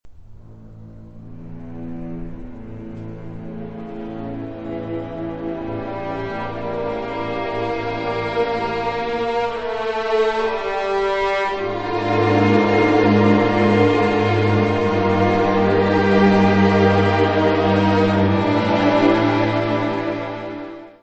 : stereo; 12 cm + folheto
Área:  Música Clássica